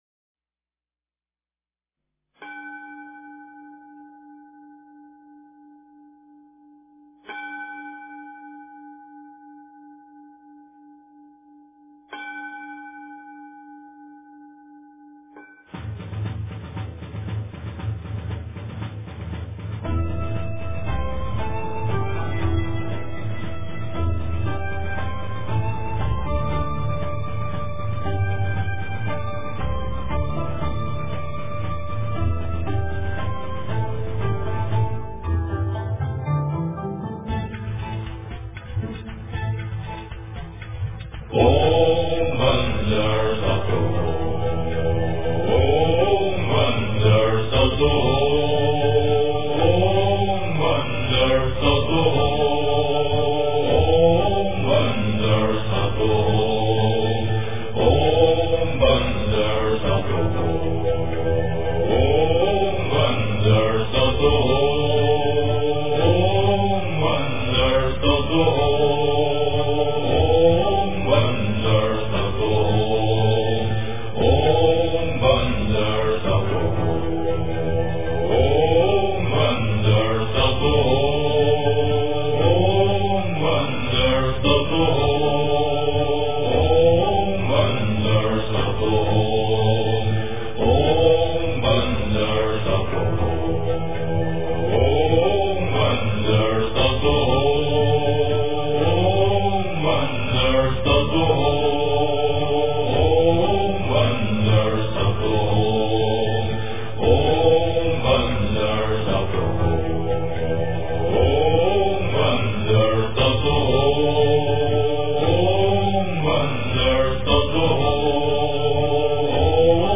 诵经
佛音 诵经 佛教音乐 返回列表 上一篇： 大悲咒-梵音 下一篇： 般若波罗蜜多心经 相关文章 佛母大孔雀明王普成就陀罗尼--佛教音乐 佛母大孔雀明王普成就陀罗尼--佛教音乐...